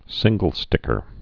(sĭnggəl-stĭkər)